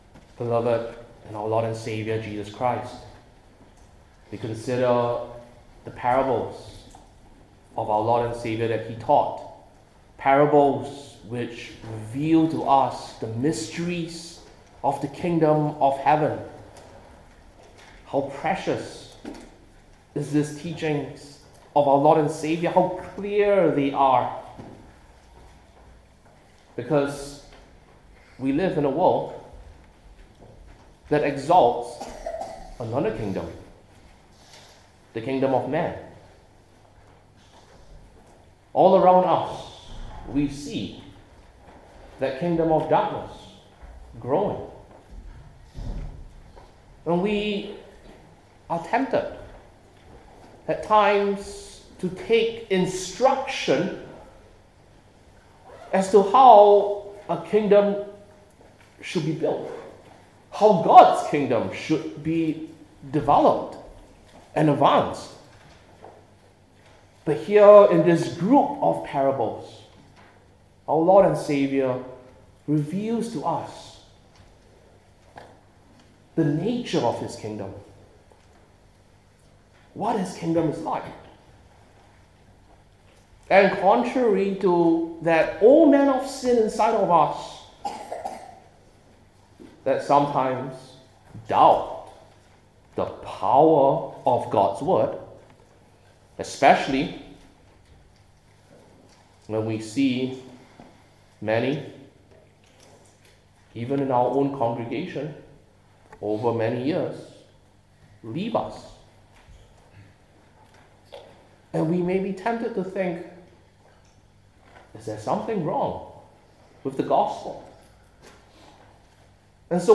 Matthew 13:31-33 Service Type: New Testament Individual Sermons I. The Smallest Seed Sown II.